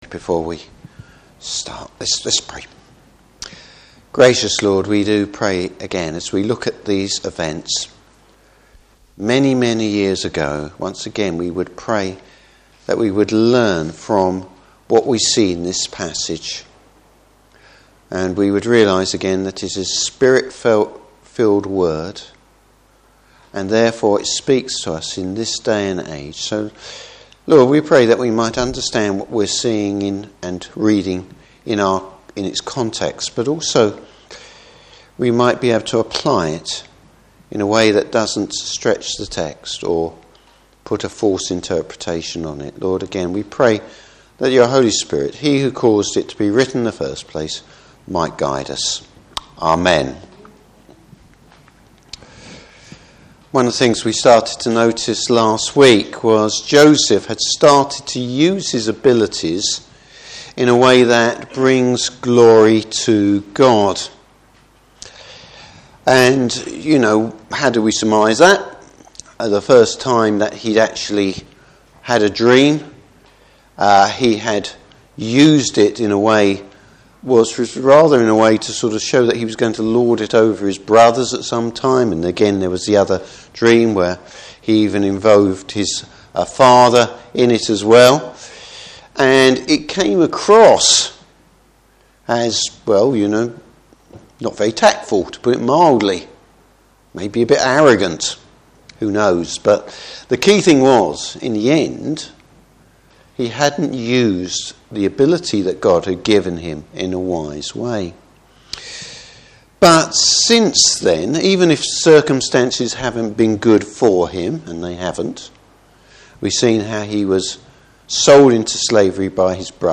Service Type: Evening Service Joseph’s maturity in using his gift.